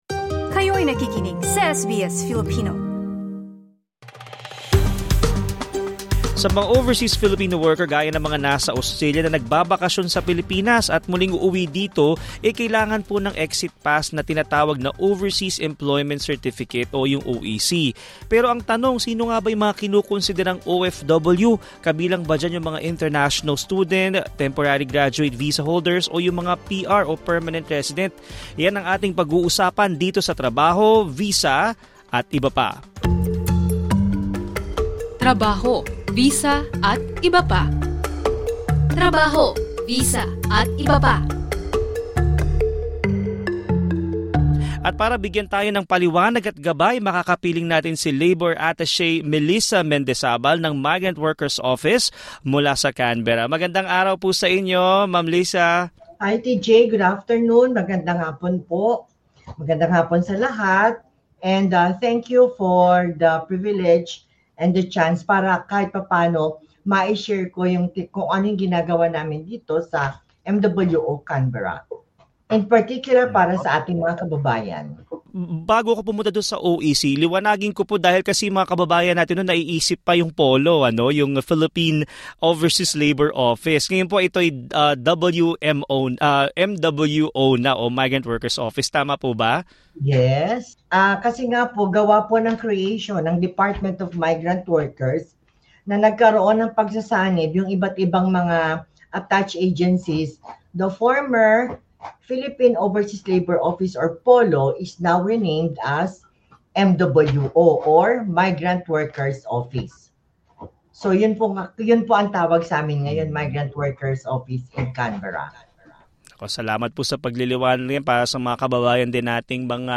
In an episode of Trabaho, Visa, atbp., Migrant Workers Office Labor Attaché Melissa Mendizabal shared insights on the purpose and process of obtaining an Overseas Employment Certificate (OEC) for OFWs in Australia.